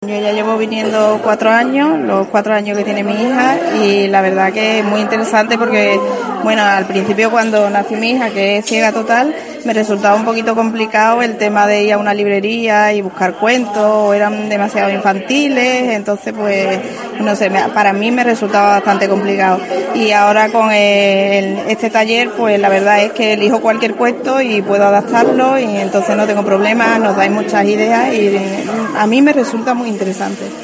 Una madre, fans declarada de esta iniciativa, y que de hecho ha participado en sus cuatro convocatorias,